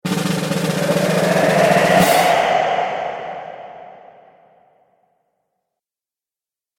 دانلود آهنگ باد 3 از افکت صوتی طبیعت و محیط
دانلود صدای باد 3 از ساعد نیوز با لینک مستقیم و کیفیت بالا
جلوه های صوتی